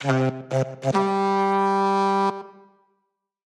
Minecraft Version Minecraft Version 25w18a Latest Release | Latest Snapshot 25w18a / assets / minecraft / sounds / item / goat_horn / call0.ogg Compare With Compare With Latest Release | Latest Snapshot